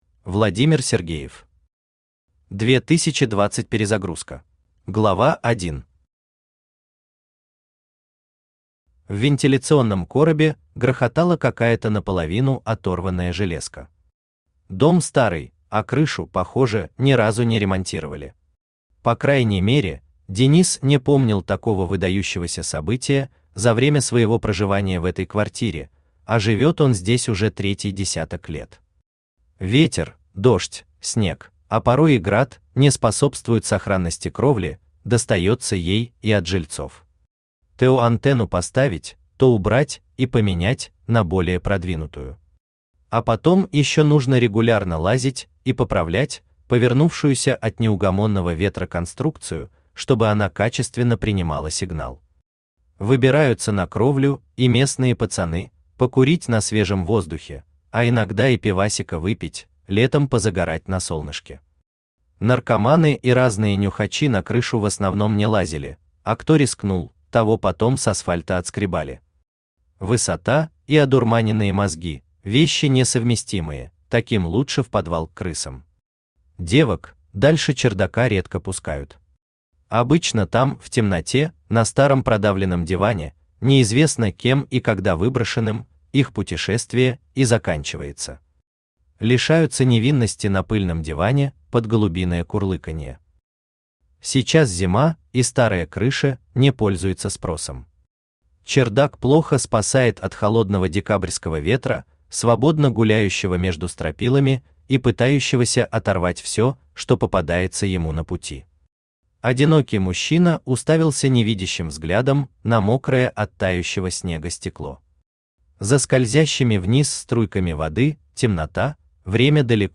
Aудиокнига 2020 Перезагрузка Автор Владимир Алексеевич Сергеев Читает аудиокнигу Авточтец ЛитРес.